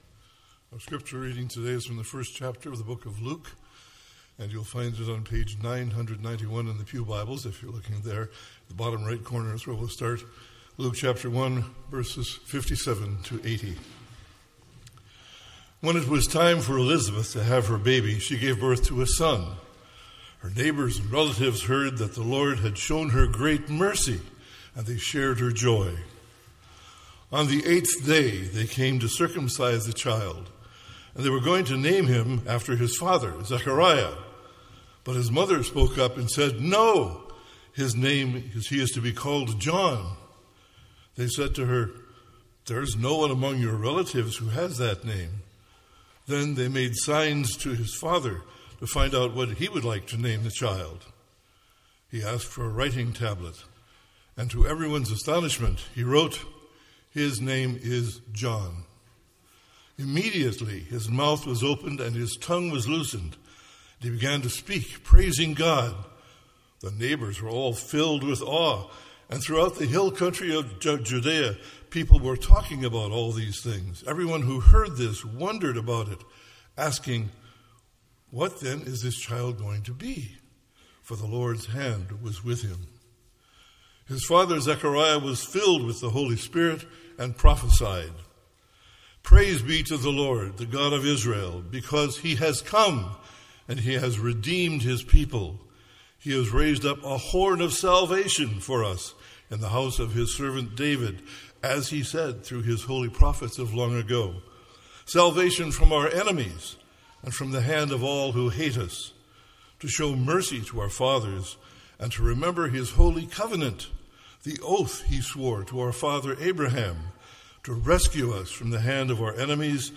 MP3 File Size: 33.1 MB Listen to Sermon: Download/Play Sermon MP3